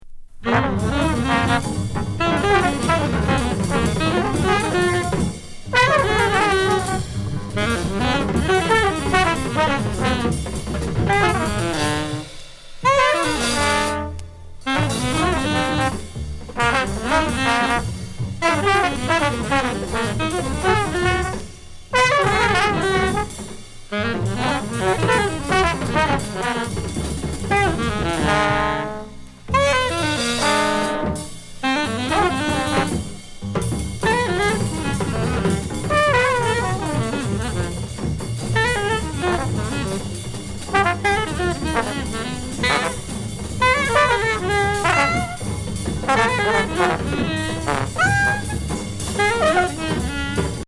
trb